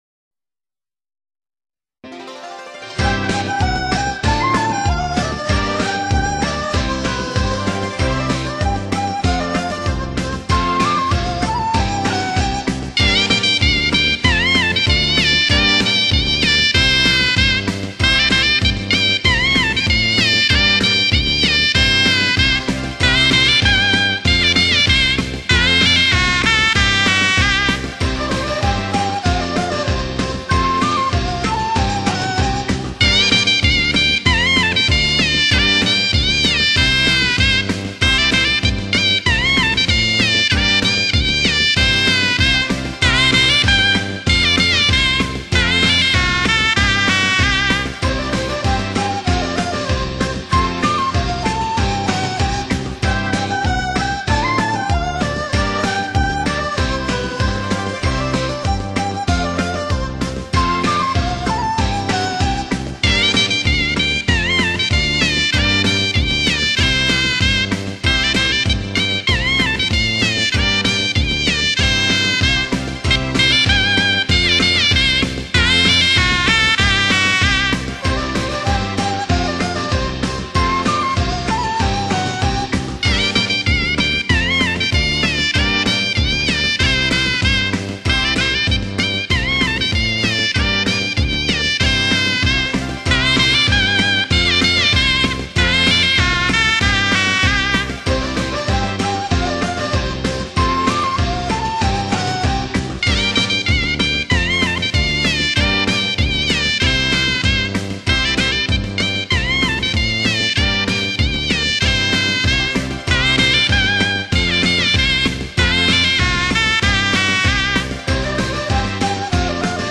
中国吹奏乐